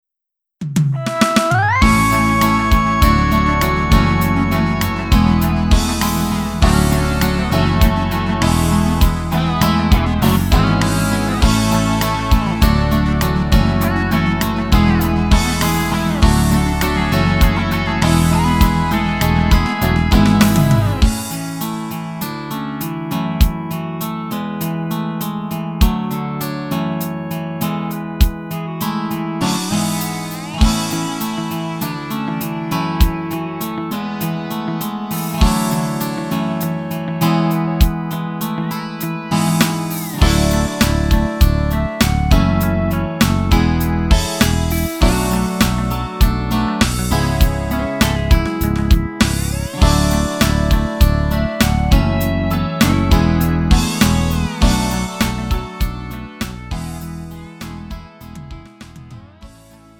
음정 -1키 3:52
장르 가요 구분 Lite MR